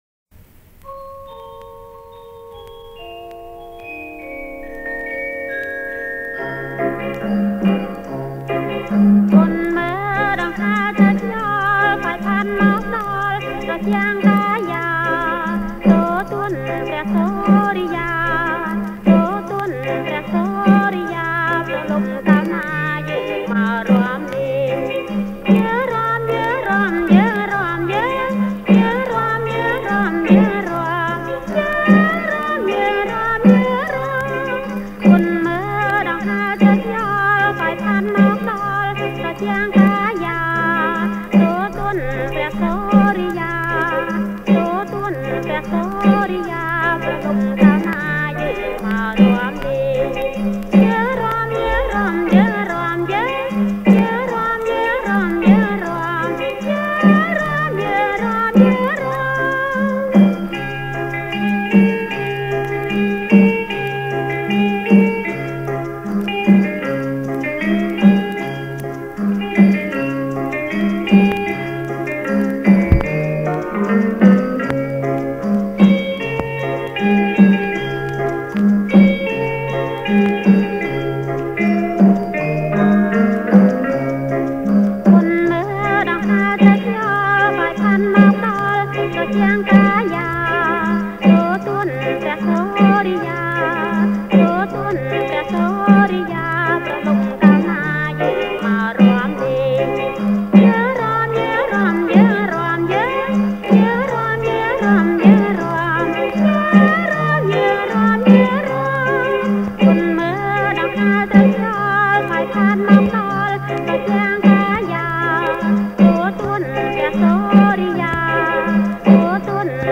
• ប្រគំជាចង្វាក់ រាំវង់
ប្រគំជាចង្វាក់ រាំវង់